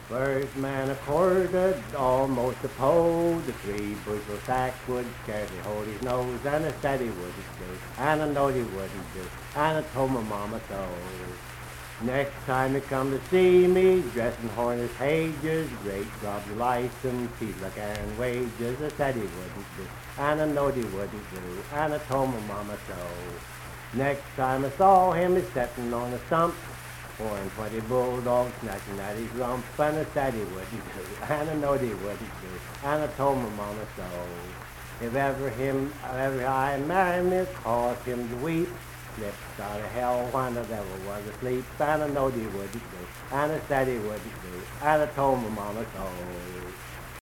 Unaccompanied vocal music
Verse-refrain 4(4). Performed in Hundred, Wetzel County, WV.
Voice (sung)